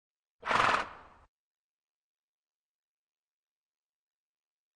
Лошадь фыркает